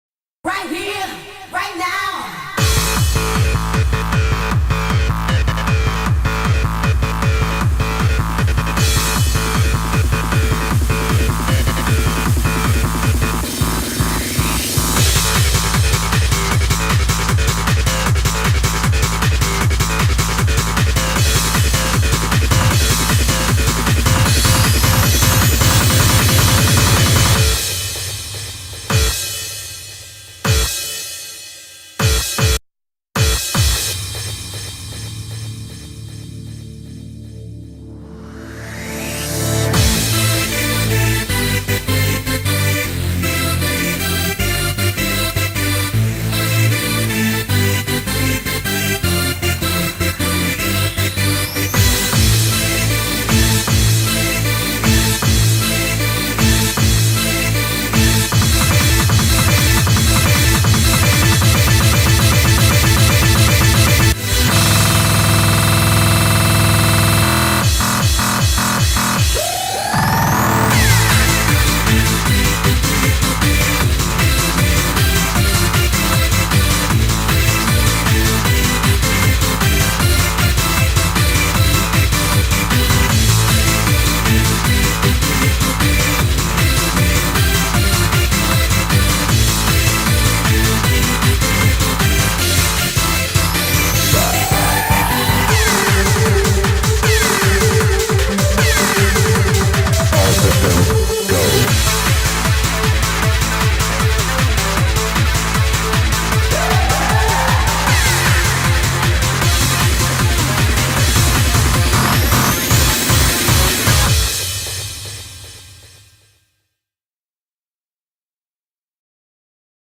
BPM155
Audio QualityPerfect (High Quality)
Comments[HARD DANCE]